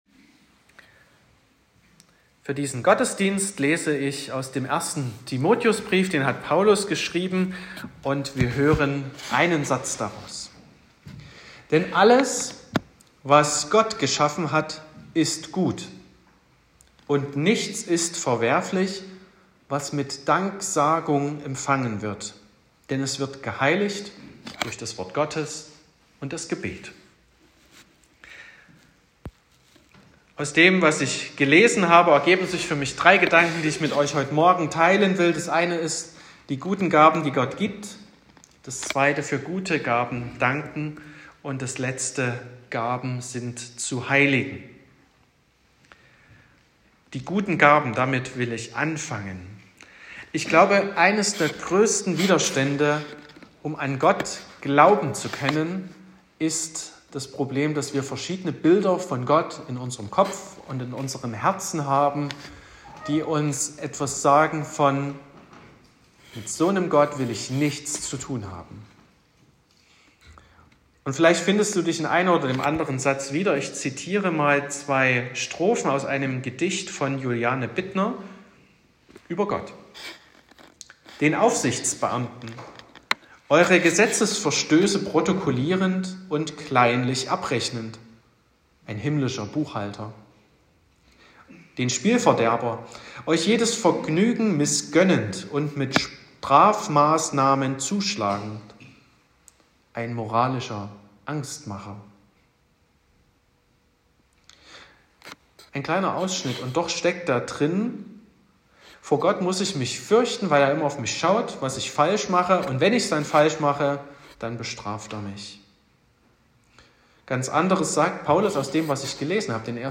06.10.2024 – Erntedankfest und Kirchweihfestgottesdienst
Predigt und Aufzeichnungen